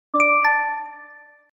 Line Notification